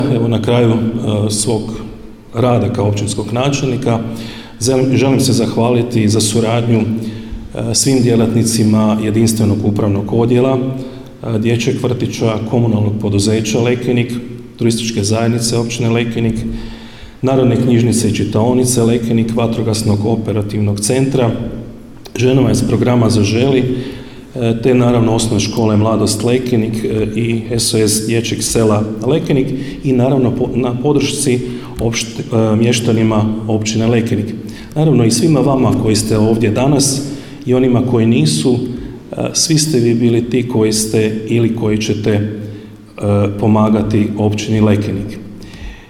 Obilježba je zaključena prigodnim programom u Društvenom domu i turističko-informativnom centru u Lekeniku, gdje su načelnik Ivica Perović i sada već bivši predsjednik Općinskog vijeća Marin Čačić dodijelili javna priznanja Općine Lekenik u 2025. godini.